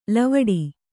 ♪ lavaḍi